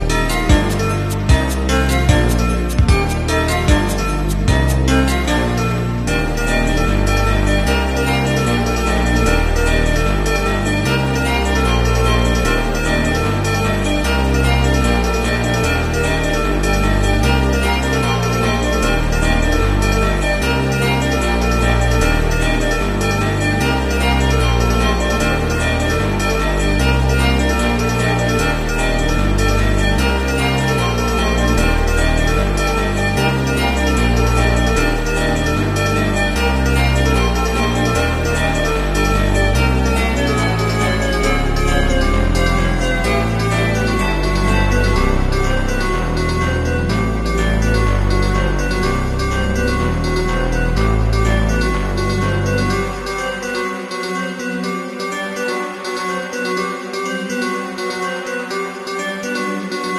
Our New Generation (38mm) Wastegate sound effects free download